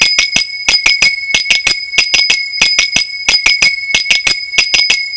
Gallop  (series de tres)
Es el patrón de crótalos mas simple que hay. Es una serie de tres choques, cada uno con una distinta mano.
takaDUM // takaDUM // takaDUM // takaDUM //